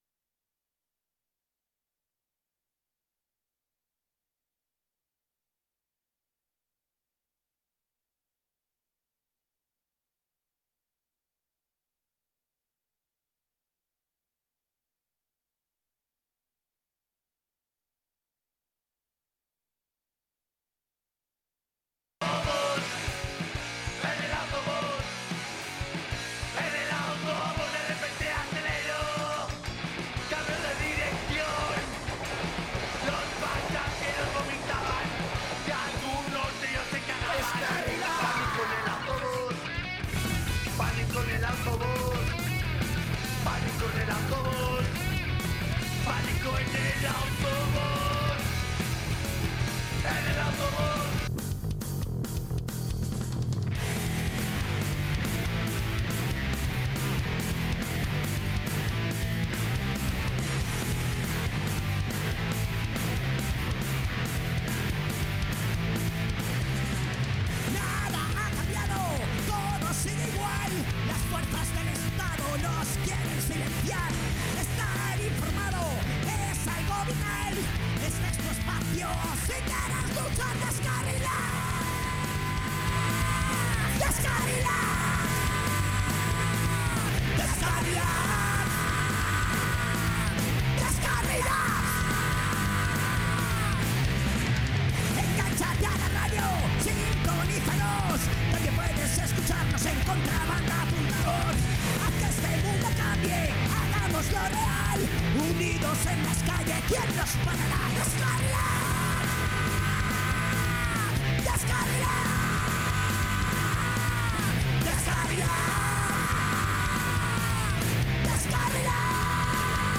En este 100 programa de Deskarilats hacemos un repaso a la trayectoria de estos programas y comentamos un poco lo que nos gusto lo que recordamos de estos programas transcurridos mientras comentamos estas historias vamos poniendo diferentes músicas relacionadas